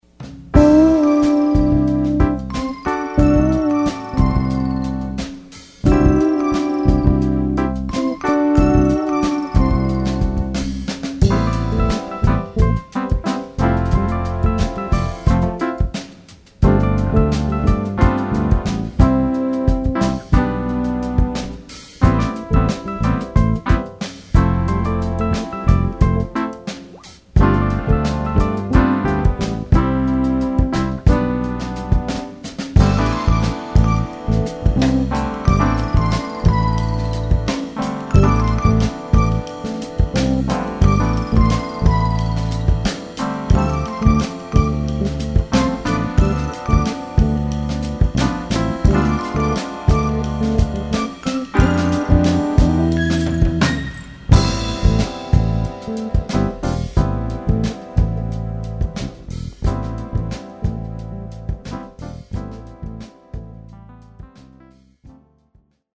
bass\drums\keys\guitar